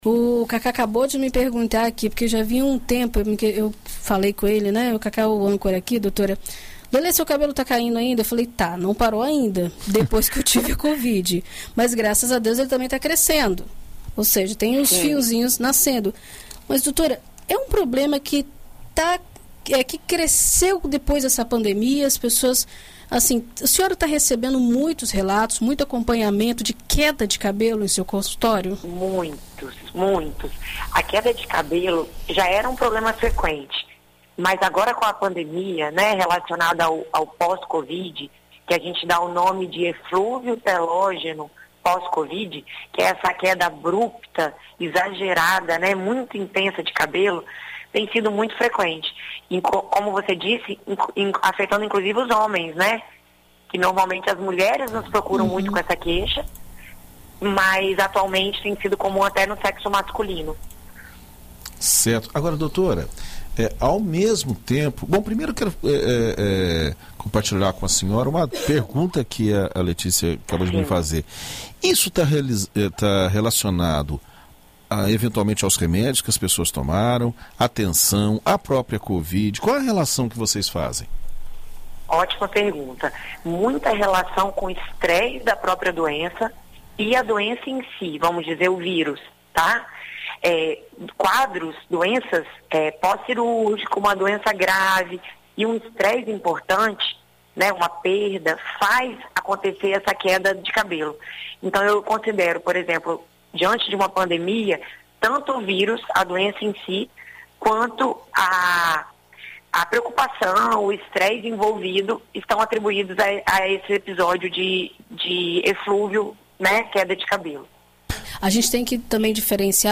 ENT-QUEDA-DE-CABELO.mp3